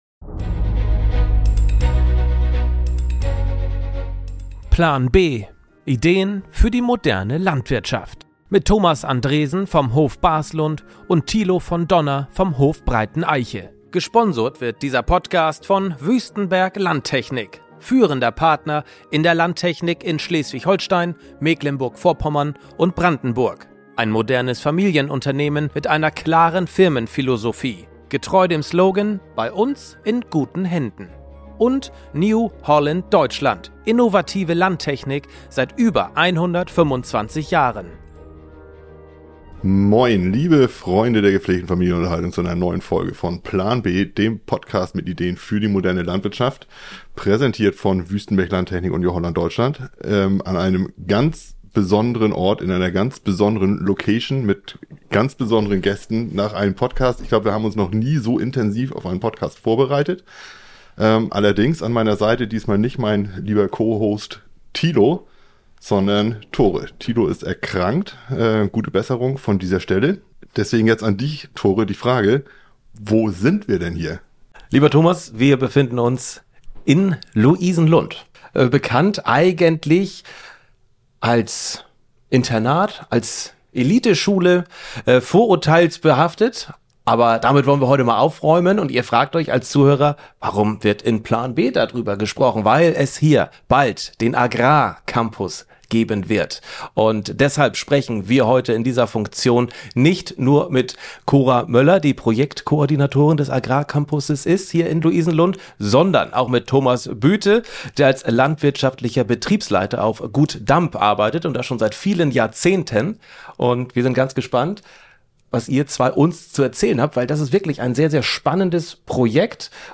Das Gespräch fand im inspirierenden Umfeld von Louisenlund statt, einem Ort, der für seine Verbindung von Bildung und Praxis bekannt ist. Dabei diskutieren wir auch über die Herausforderungen und Chancen, die sich ergeben, wenn Landjugendliche und Internatsschülerinnen und -schüler aufeinandertreffen.